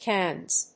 /kˈæn(米国英語)/